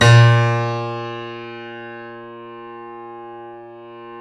Index of /90_sSampleCDs/Roland - Rhythm Section/KEY_YC7 Piano ff/KEY_ff YC7 Mono